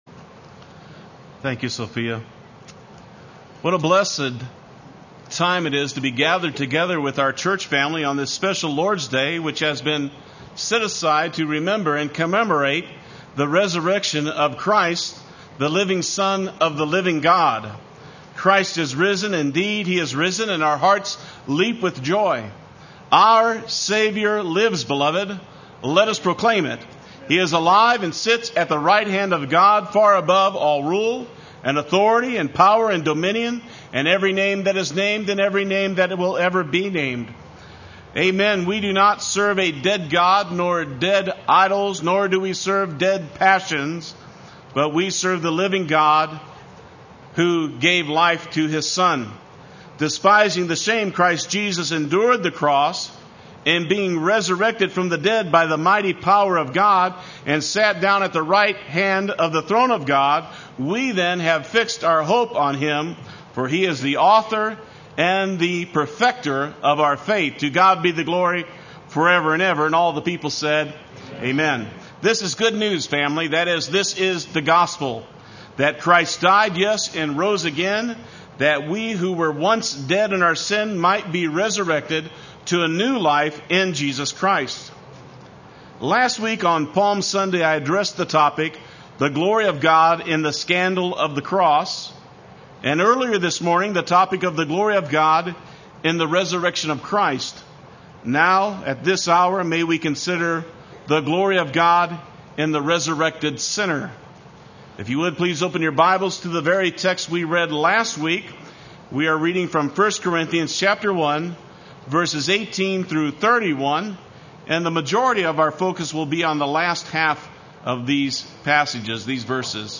Play Sermon Get HCF Teaching Automatically.
The Glory of God in the Resurrected Sinner Sunday Worship